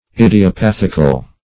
Search Result for " idiopathical" : The Collaborative International Dictionary of English v.0.48: Idiopathic \Id`i*o*path"ic\, Idiopathical \Id`i*o*path"ic*al\, a. [Cf. F. idiopathique.]
idiopathical.mp3